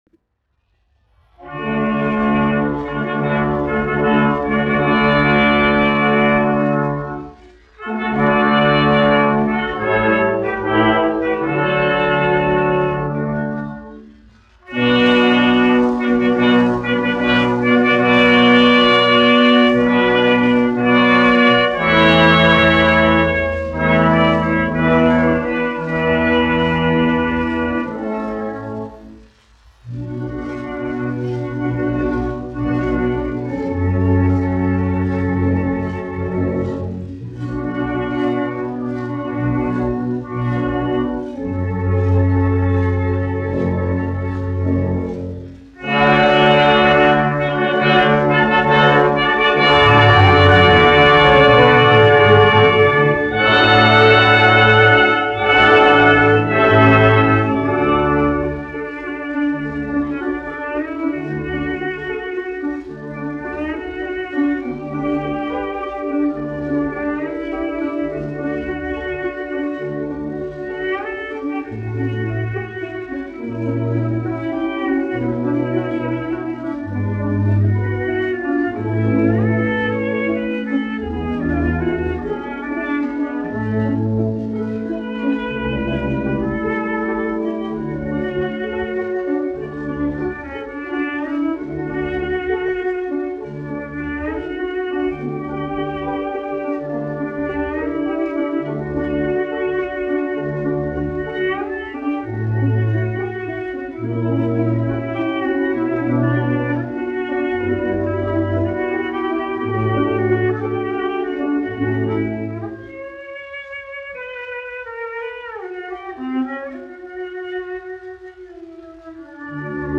1 skpl. : analogs, 78 apgr/min, mono ; 25 cm
Uvertīras
Skaņuplate
Latvijas vēsturiskie šellaka skaņuplašu ieraksti (Kolekcija)